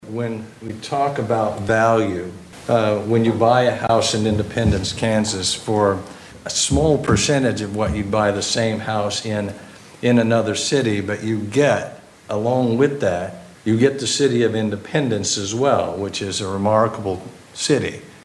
Independence Mayor Dean Hayse